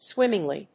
swím・ming・ly
音節swim･ming･ly発音記号・読み方swɪ́mɪŋli